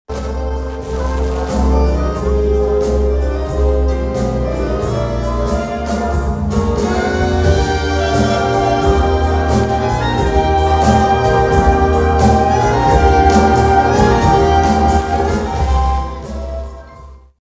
Moonlight Serenade Orchestra brings big-band sounds to Emporia’s Granada Theatre
The Moonlight Serenade Orchestra played at the Emporia Granada Theatre on Saturday.
Swing returned to the Emporia Granada Theatre with an appearance by the Moonlight Serenade Orchestra on Saturday.
The Kansas City-based band played timeless favorites from noted big-band leader Glenn Miller and crooners of the 1930s, 1940s and 1950s during the show.